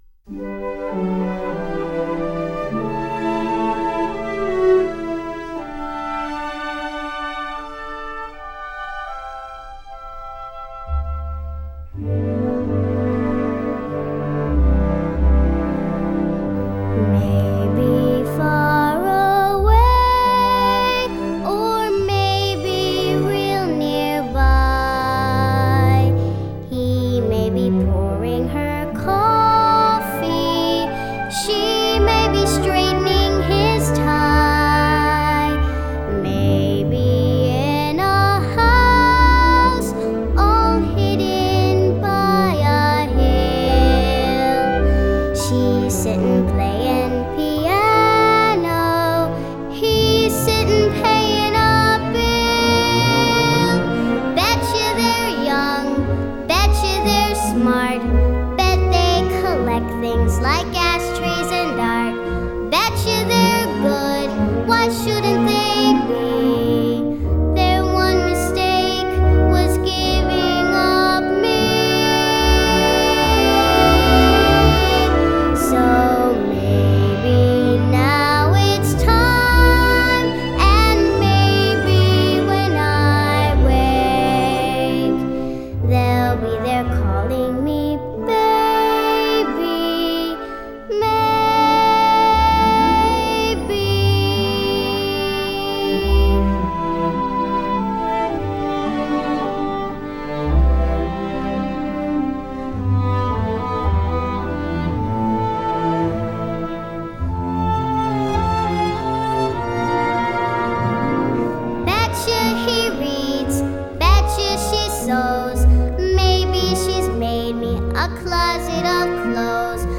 1977   Genre: Musical   Artist